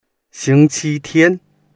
xing1qi1tian1.mp3